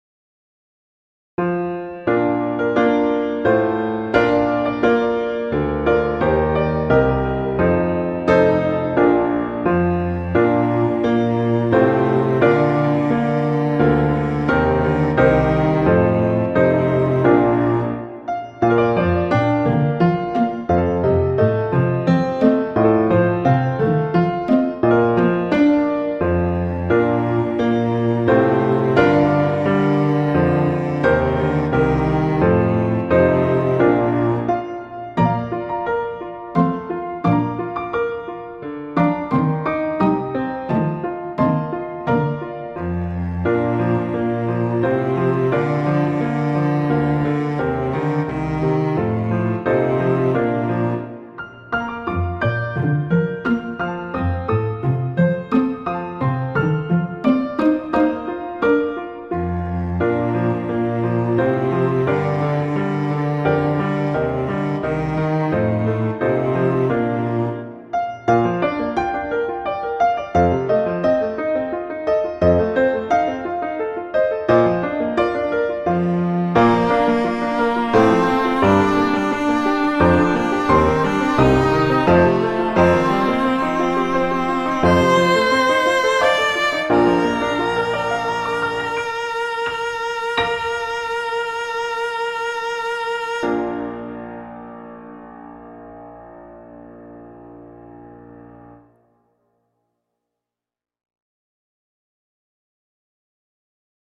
arrangements for cello and piano
cello and piano